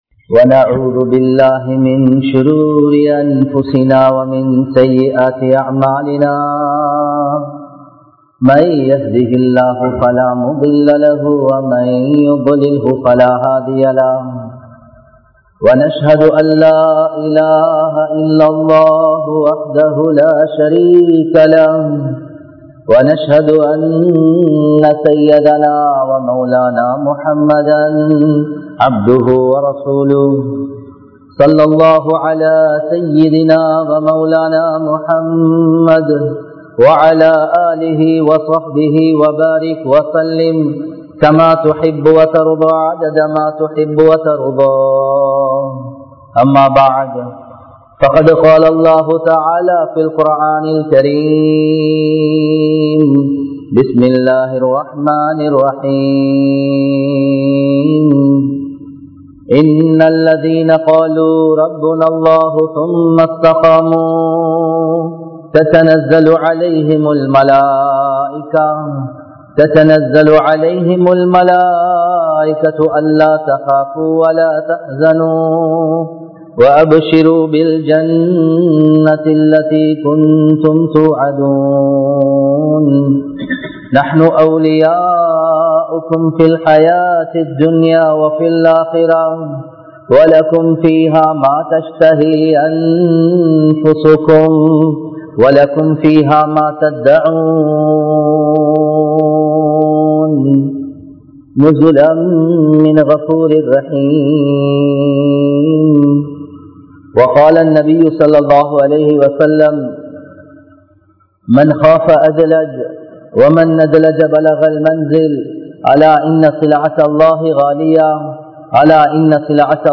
The Importance of Collective Zakath | Audio Bayans | All Ceylon Muslim Youth Community | Addalaichenai